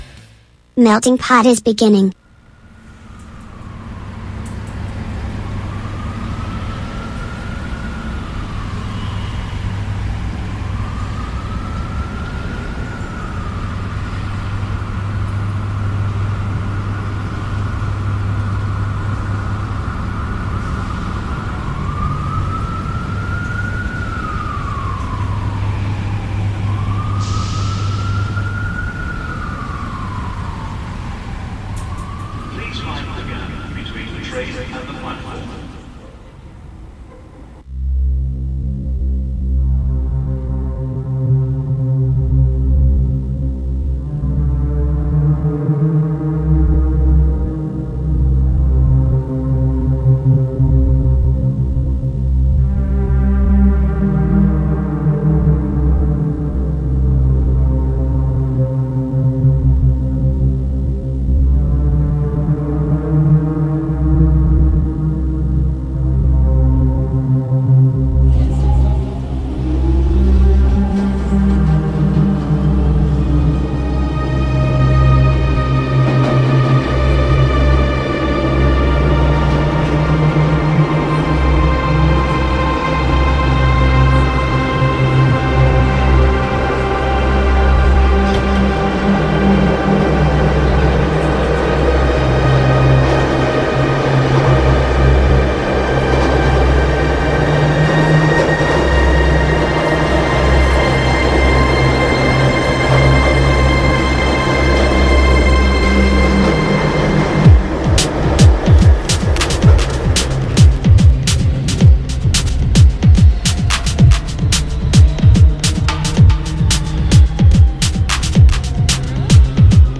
MUSICA NOTIZIE INTERVISTE A MELTINGPOT | Radio Città Aperta